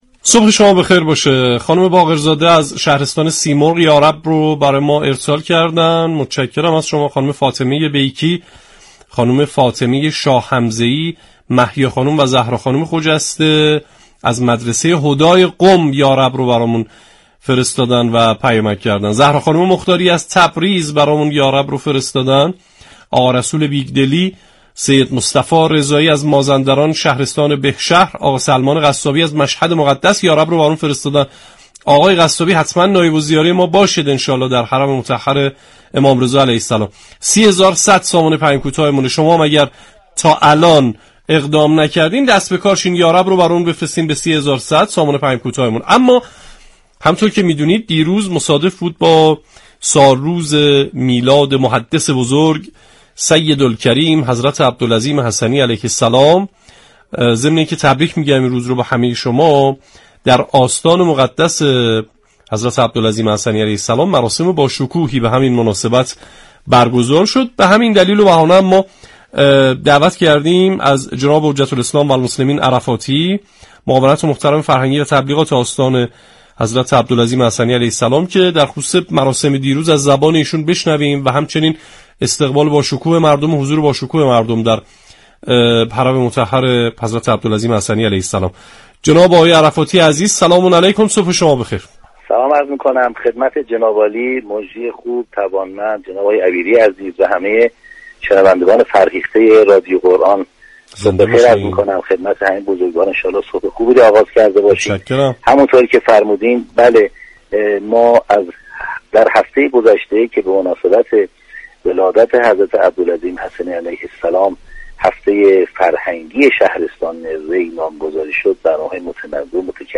در گفتگو با برنامه تسنیم رادیو قرآن به تشریح برنامه‌های هفته بزرگداشت میلاد حضرت عبدالعظیم حسنی